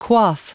Prononciation du mot quaff en anglais (fichier audio)
Prononciation du mot : quaff